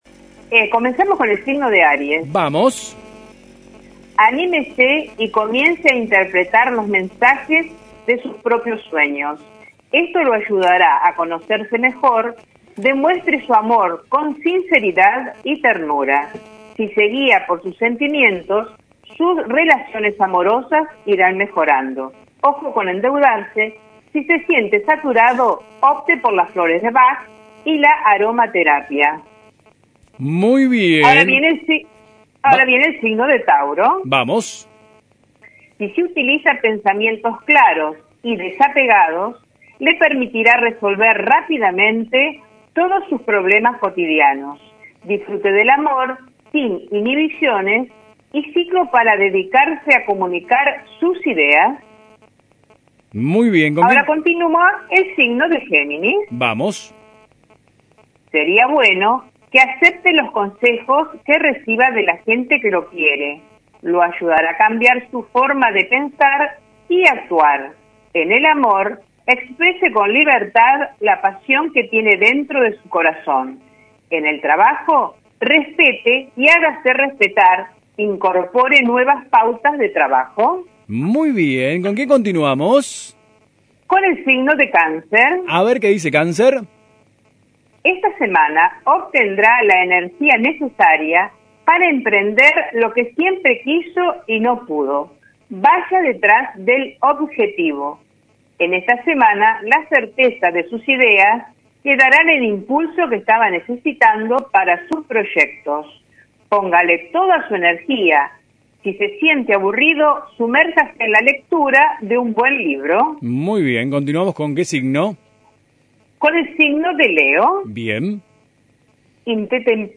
Comunicación telefónica en AM 1210